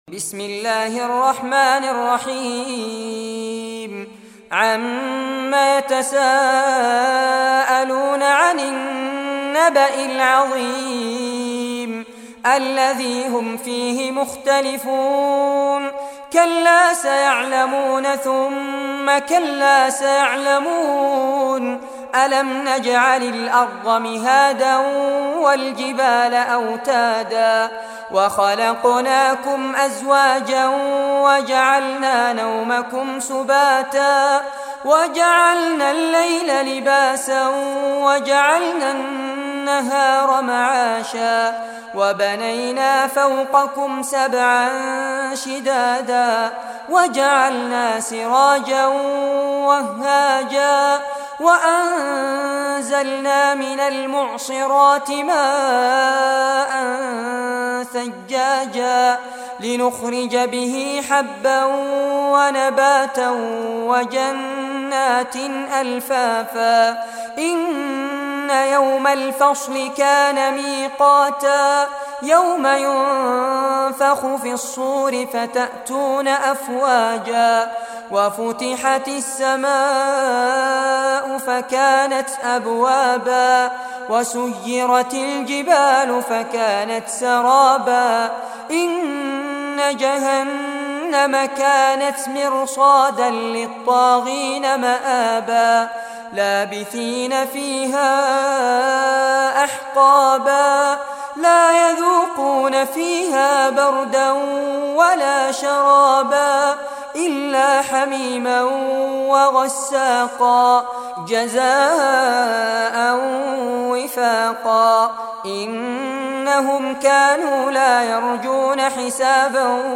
Surah An-Naba Recitation by Fares Abbad